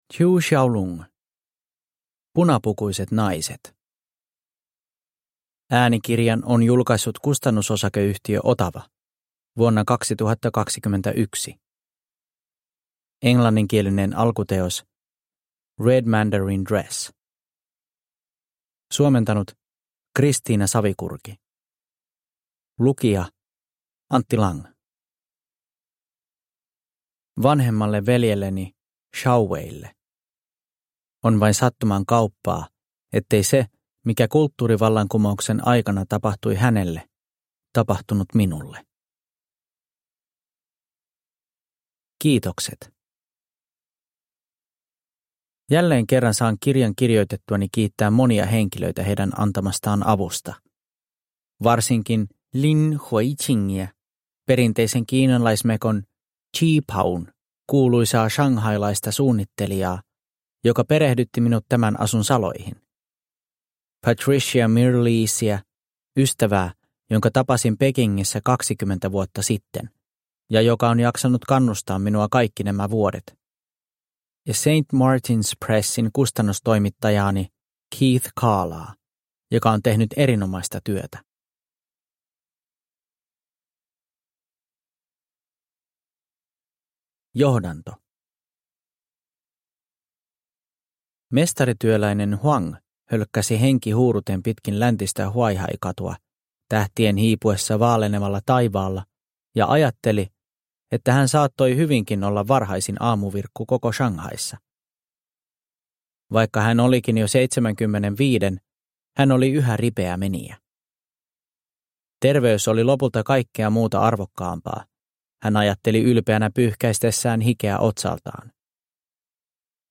Punapukuiset naiset – Ljudbok – Laddas ner